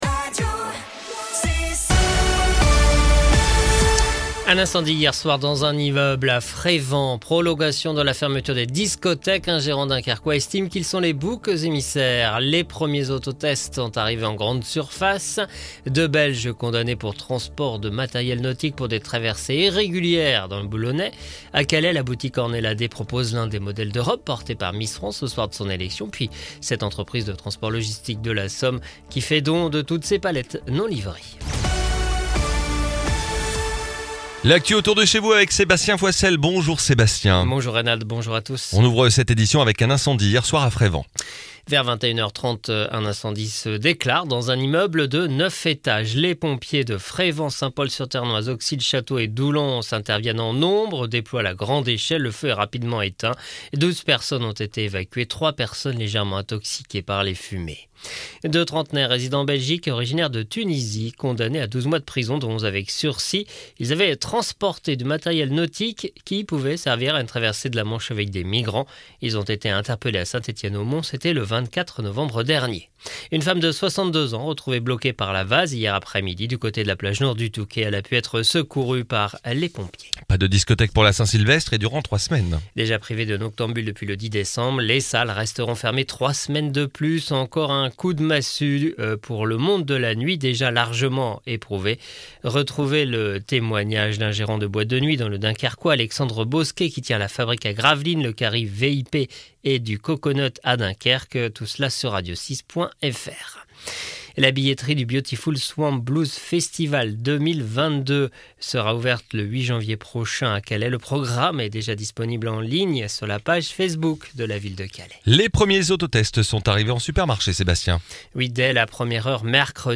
LE JOURNAL CÔTE D'OPALE ET CÔTE PICARDE DU jeudi 30 décembre 2021